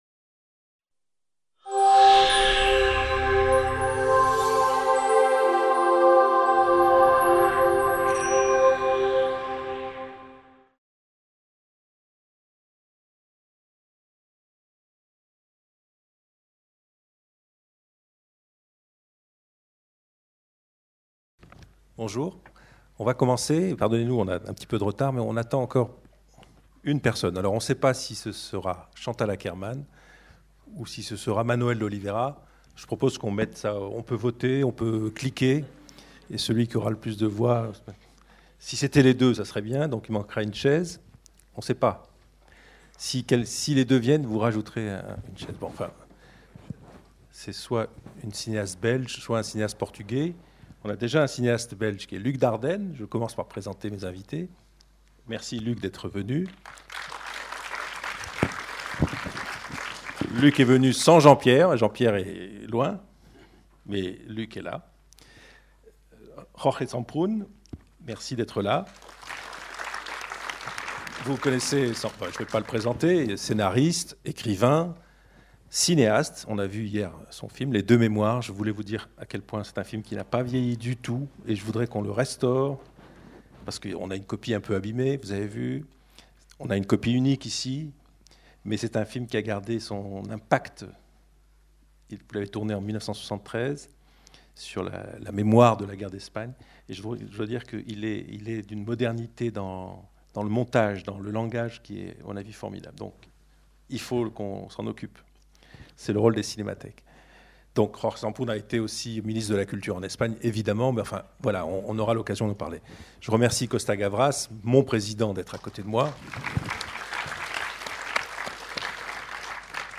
Rencontre animée par Serge Toubiana.